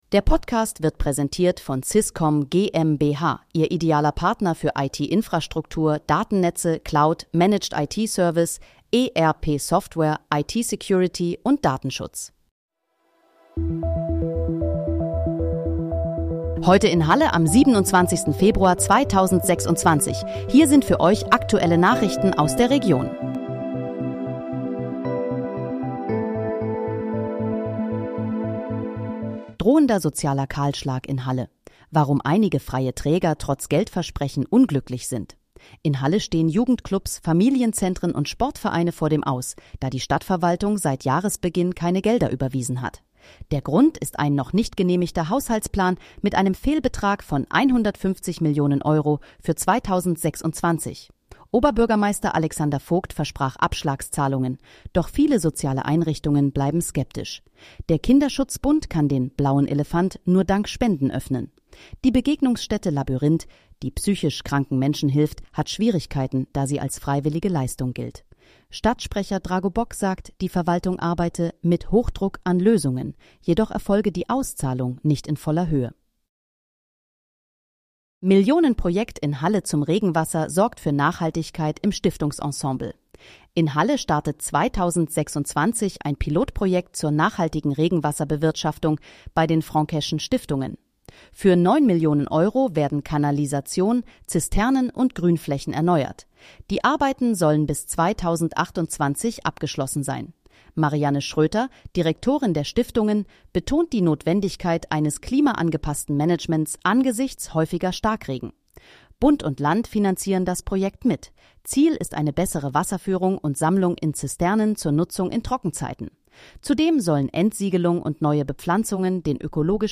Heute in, Halle: Aktuelle Nachrichten vom 27.02.2026, erstellt mit KI-Unterstützung